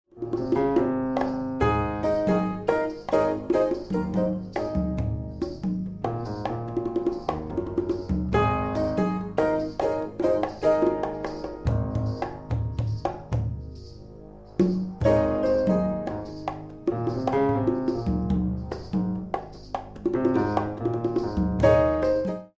4/4  mm=72